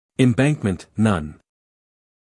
英音/ ɪmˈbæŋkmənt / 美音/ ɪmˈbæŋkmənt /